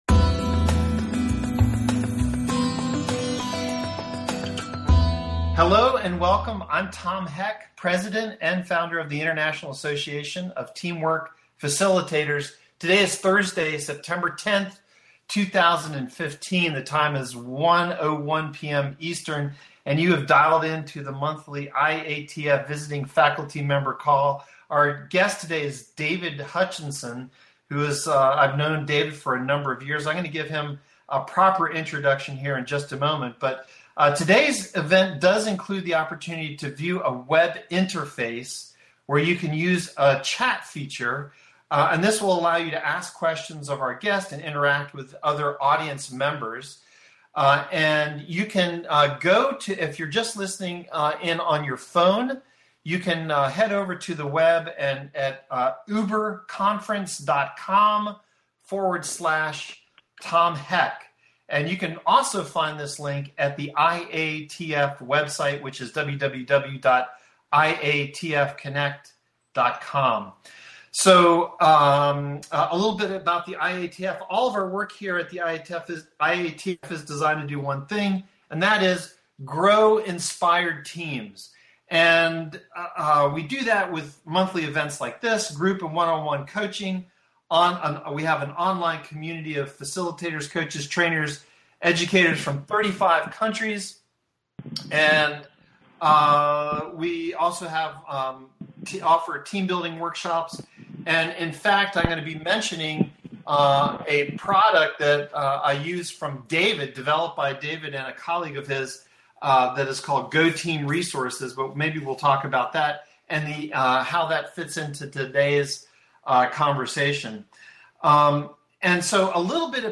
interview
In this fun TeleSeminar